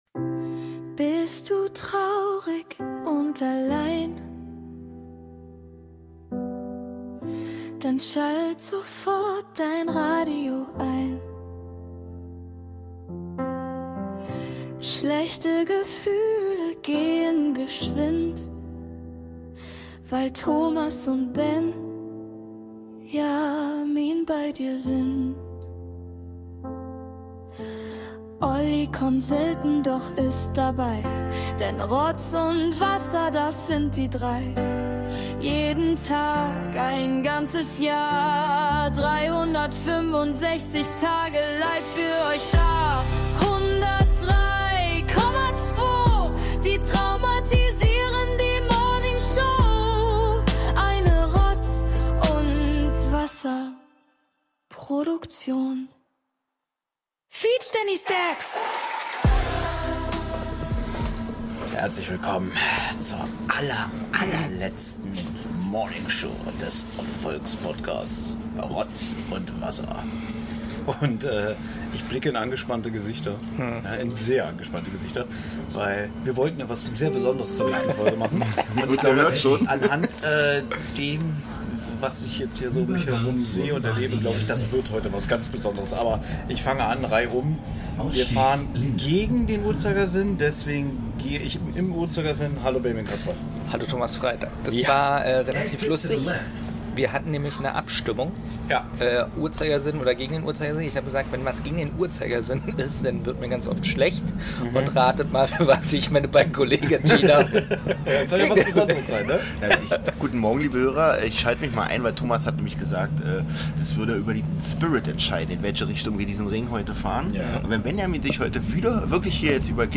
Beschreibung vor 6 Tagen *Schnief*, Wir wussten alle, dass der Tag kommen würde, und nun ist sie da, die allerletzte Morningshow. Qualitätsmäßig machen mir eine Zeitreise in das Jahr 2005. (Sorry, für den schlechten Sound), aber eigentlich mögt ihr es ja so dreckig... Als Entschädigung begleitet ihr die drei, ganz real und ungefiltert durch Berlin..